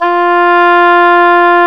WND OBOE3 F4.wav